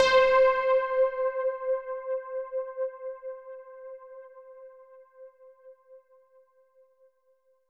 SOUND  C4.wav